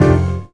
JAZZ STAB 20.wav